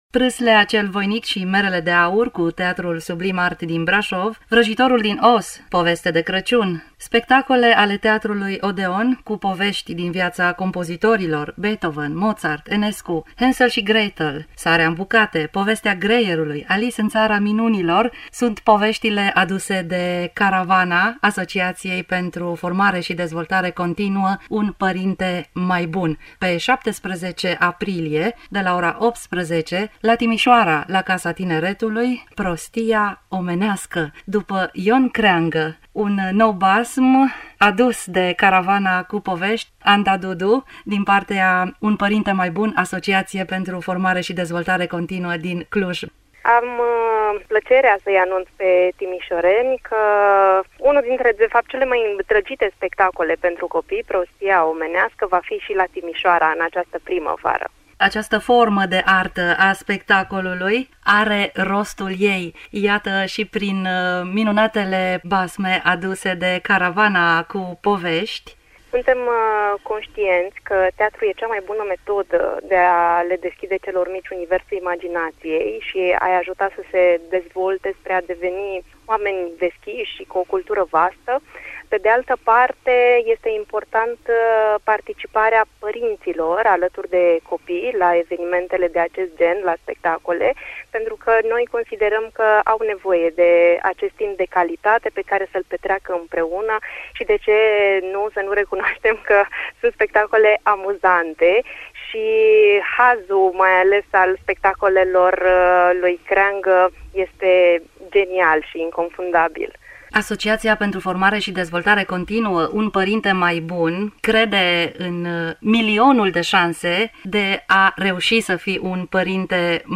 Detalii, în dialogul